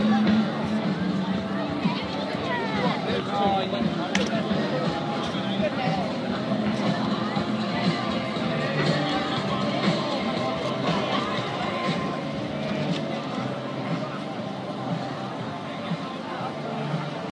Fans walking through the turnstiles against St Kilda
Fans descend upon Metricon Stadium before the match gets underway